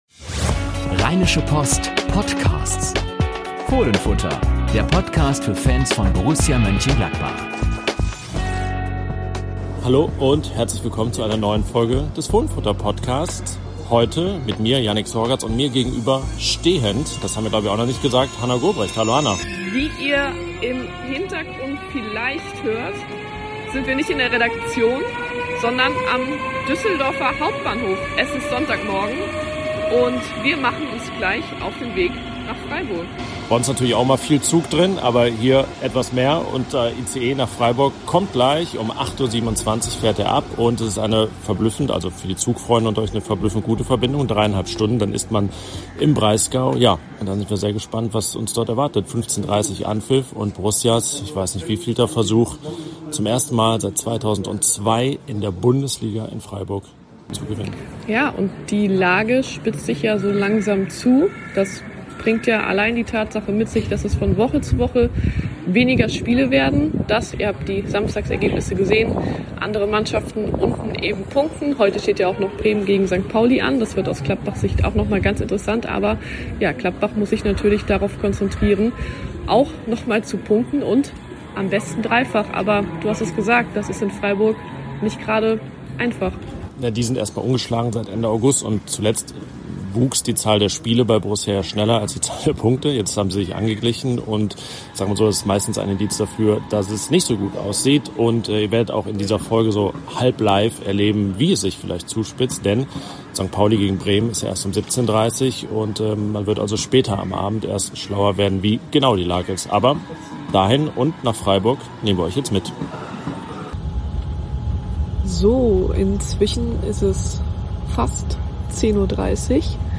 Rund um die 1:2-Niederlage beim SC Freiburg nehmen unsere Reporter die Gladbach-Fans mit auf Auswärtstour. Sie schildern ihre Live-Eindrücke aus dem Zug, aus dem Stadion und von der Pressekonferenz. Über allem steht die Frage: Wer oder was kann Borussia noch retten?